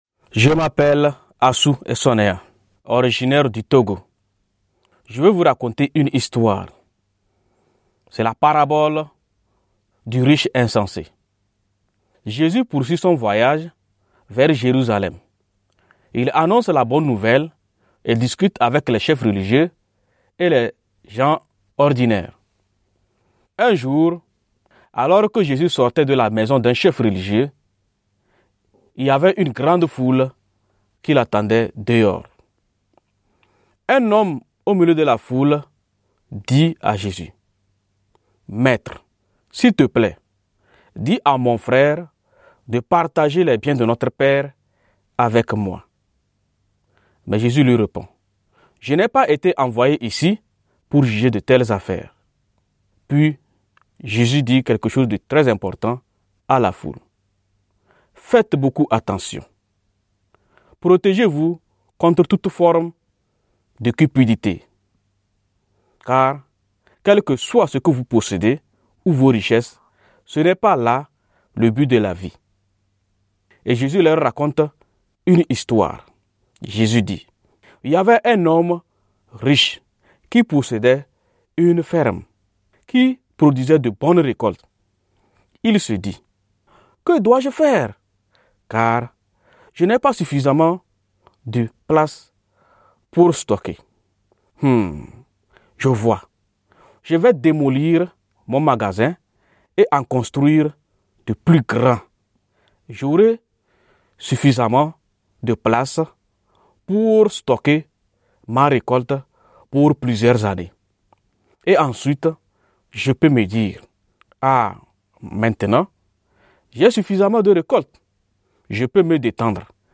raconter l'histoire de Jésus sur ce qui est arrivé au riche fou.